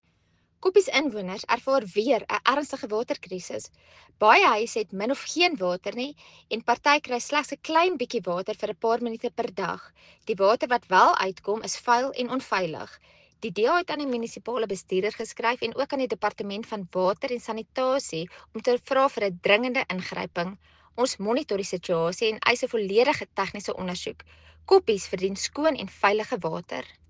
Afrikaans soundbites by Cllr Carina Serfontein and